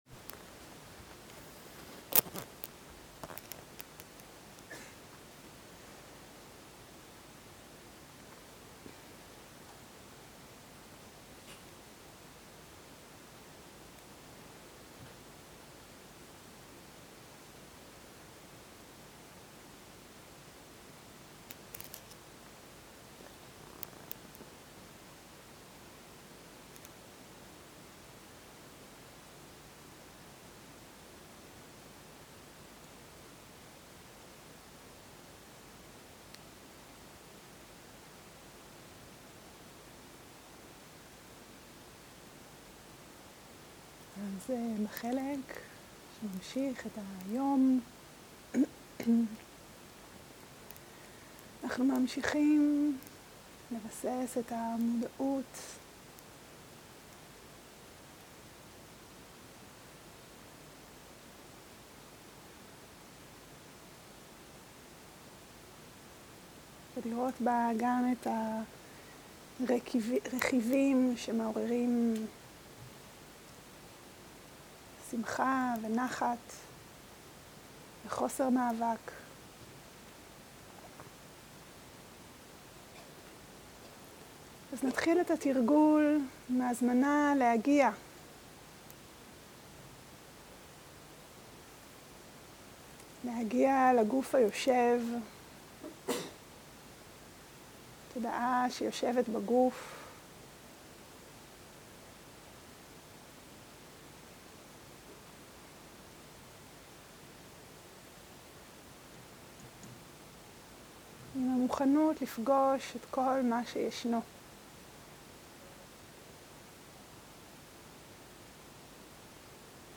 מדיטציה מונחית
עברית איכות ההקלטה: איכות גבוהה מידע נוסף אודות ההקלטה